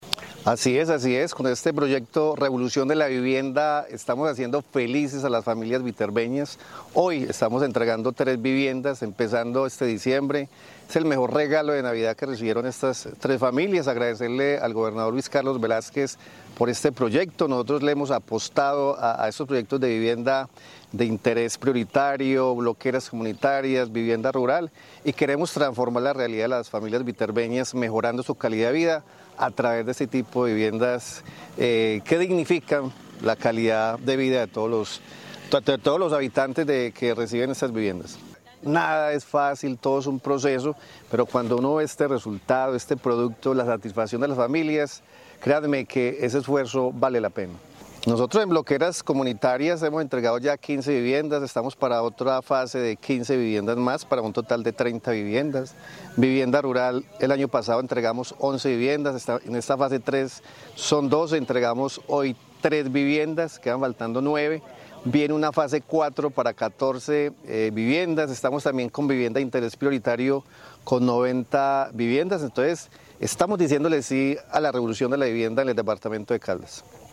Alcalde de Viterbo, Jhon Mario Giraldo Arrubla
Jhon_Mario_Giraldo_Arrubla_alcalde_de_Viterbo.mp3